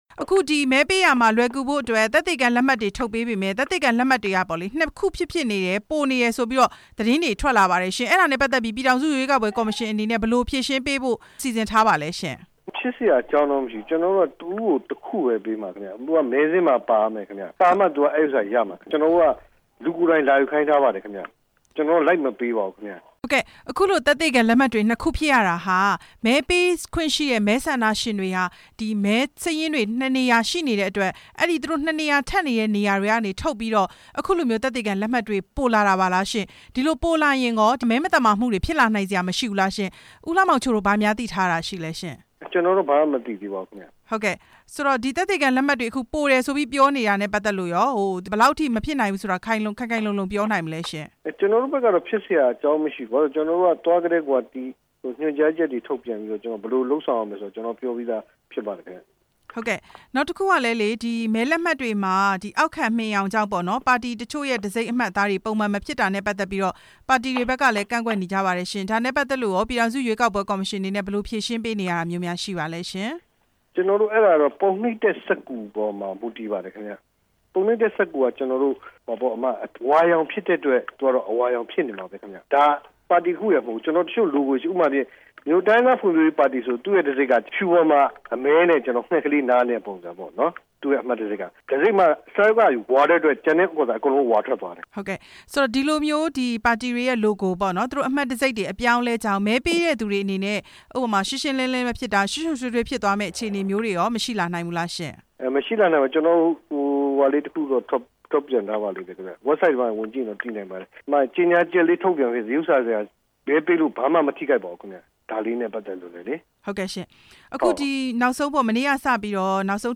မဲဆန္ဒရှင်သက်သေခံလက်မှတ် နှစ်ခုရရှိနေတဲ့အကြောင်း မေးမြန်းချက်